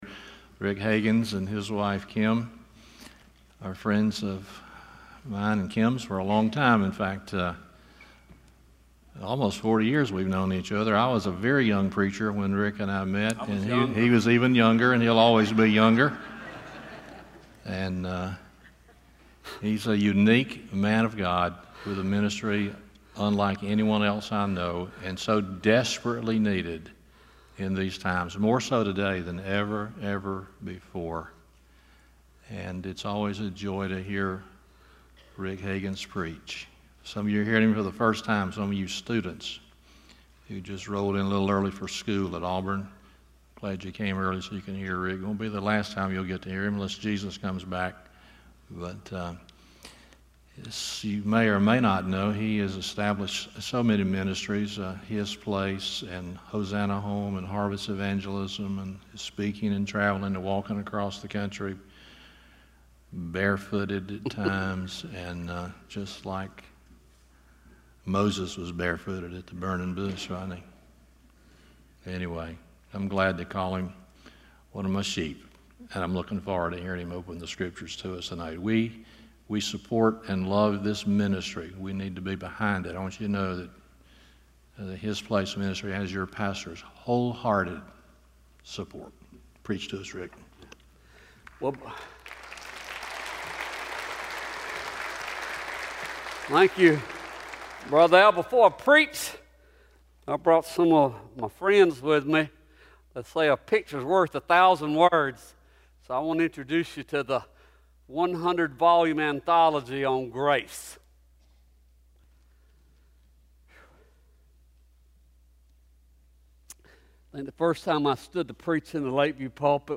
Genesis 13:14-18 Service Type: Sunday Evening Things Look Different When You're With The Father